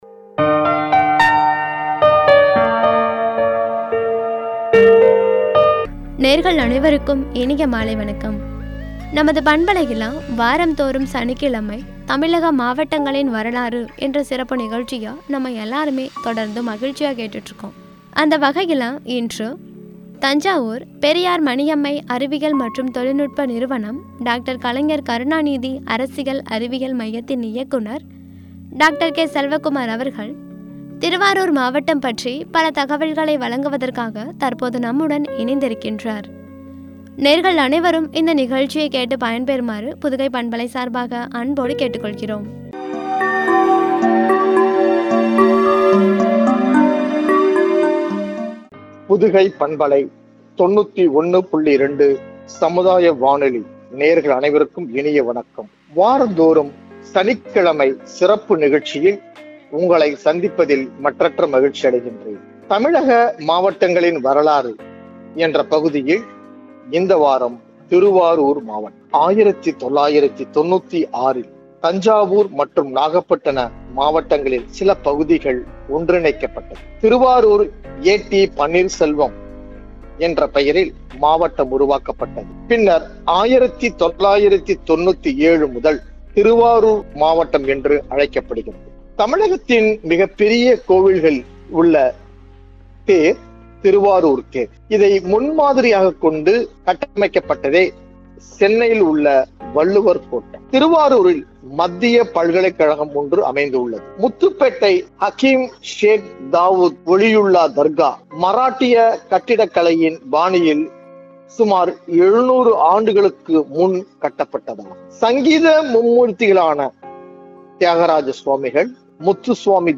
என்ற தலைப்பில் வழங்கிய உரை.